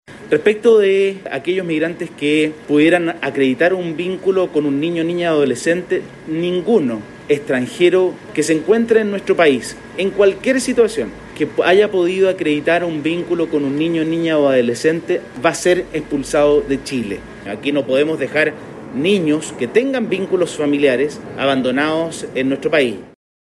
El Subsecretario del Interior, Juan Francisco Galli, aclaró que mientras se confirmen los vínculos familiares de un migrante con un menor, esa persona no podrá ser expulsada.